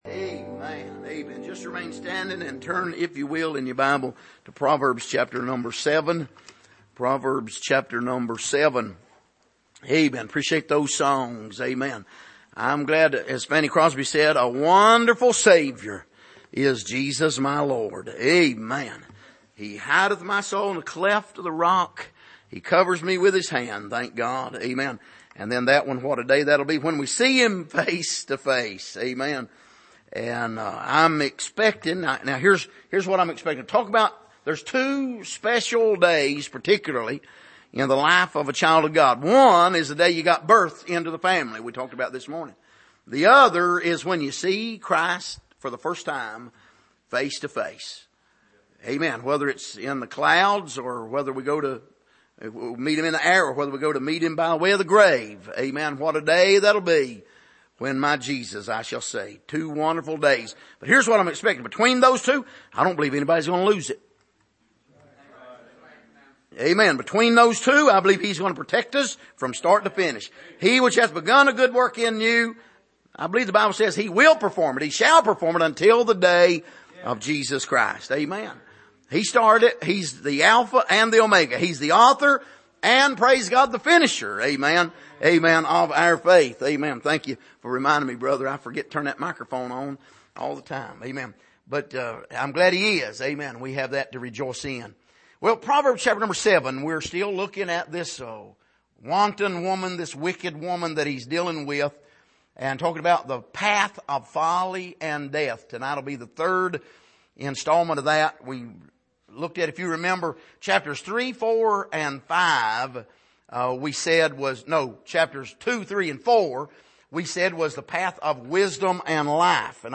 Passage: Proverbs 7:1-27 Service: Sunday Evening